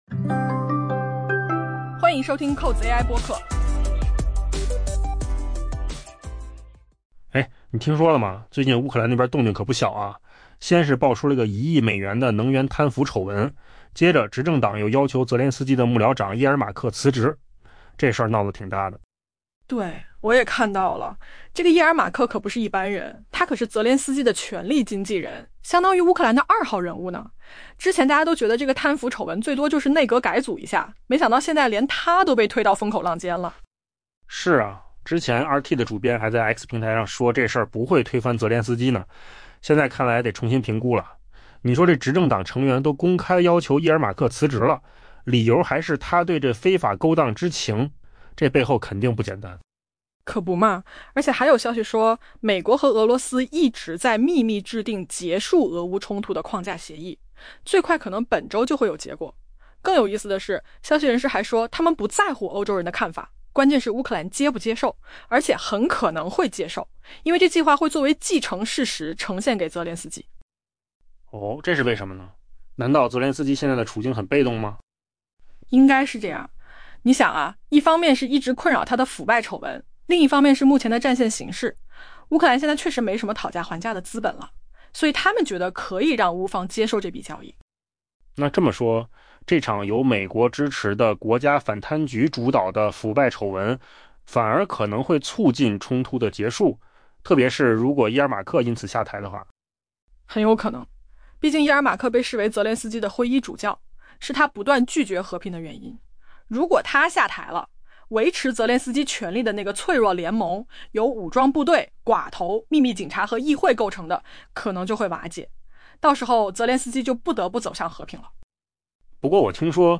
AI 播客：换个方式听新闻 下载 mp3 音频由扣子空间生成 身为泽连斯基的权力经纪人、乌克兰二号人物安德烈·叶尔马克 （Andrey Yermak） 的倒台可能会瓦解那个让泽连斯基得以掌权的、由武装部队、寡头、秘密警察和议会组成的脆弱联盟。